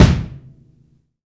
INSKICK14 -L.wav